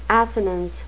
assonance (AS-oh-nans) noun
Pronunciation: